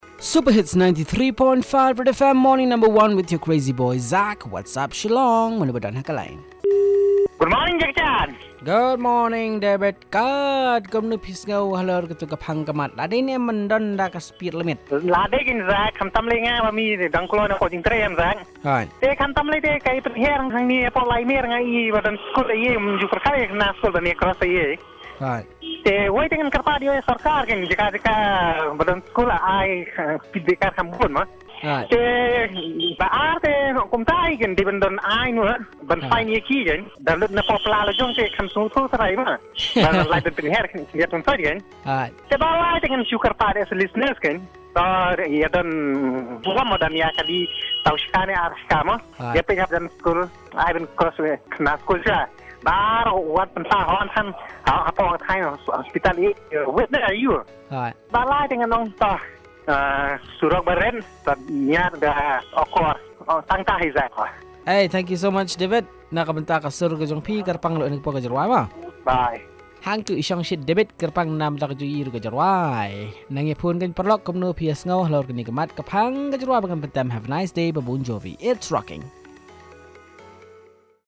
Caller 1 on speed limit for cars and bikes